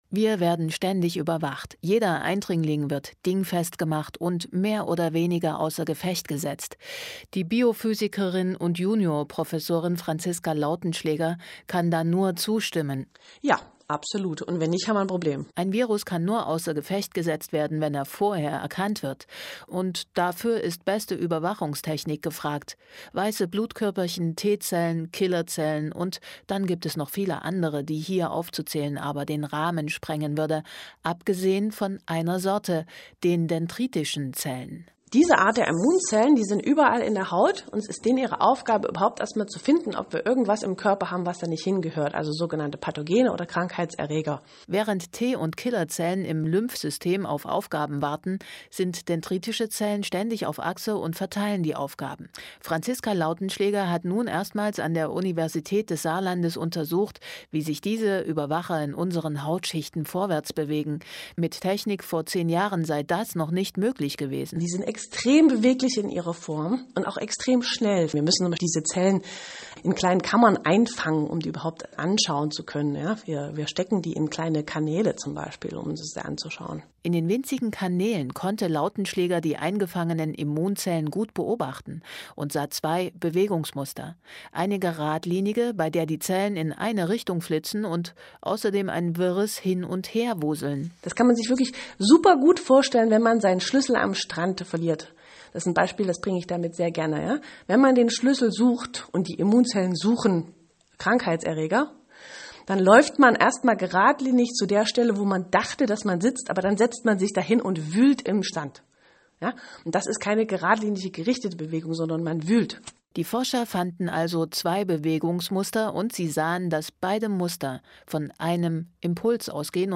link to the MDR interview
MDR-Interview.mp3